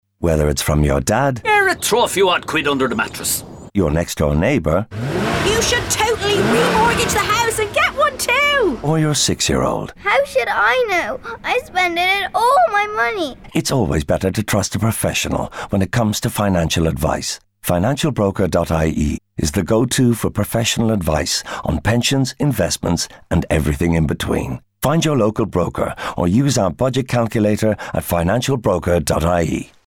Value of Financial Advice radio adverts that will run throughout April and May 2024.
brokers-ireland-value-of-advice-30sec-radio.mp3